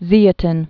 (zēə-tĭn)